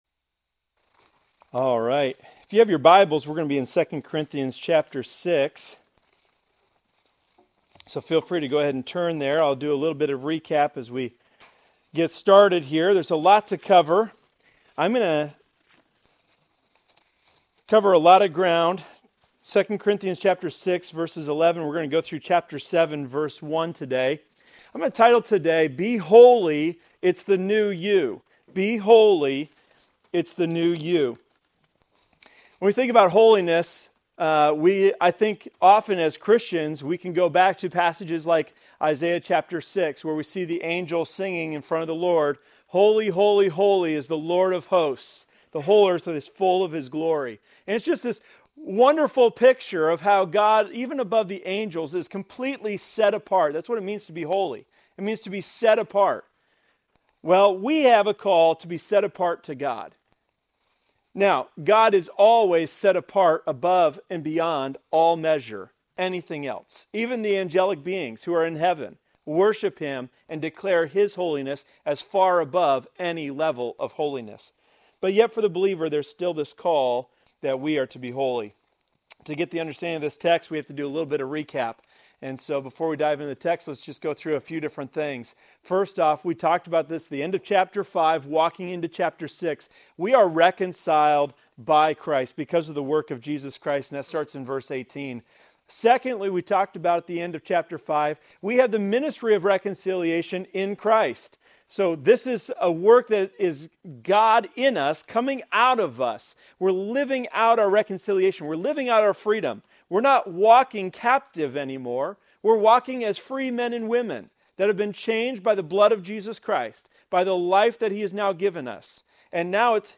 Services